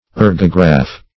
Search Result for " ergograph" : The Collaborative International Dictionary of English v.0.48: Ergograph \Er"go*graph\, n. [Gr.